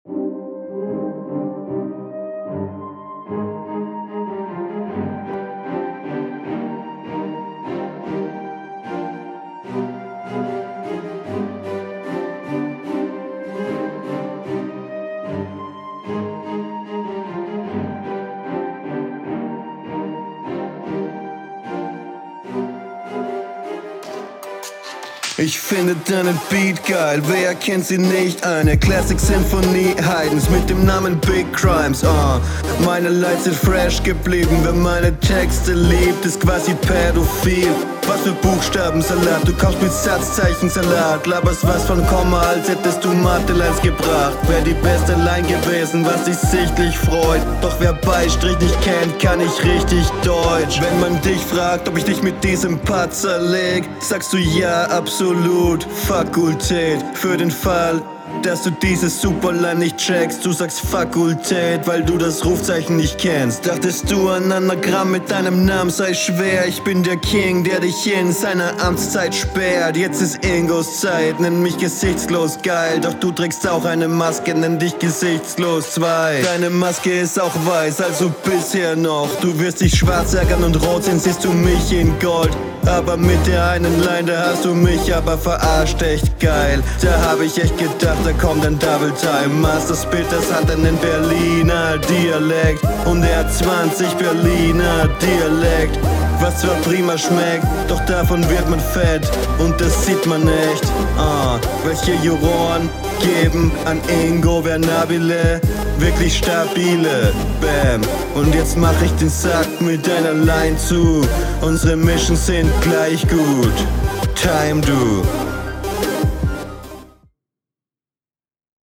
Berliner Dialekt kam auch funny als Konter Kommst obviously besser weil mehr Mühe in Flow, …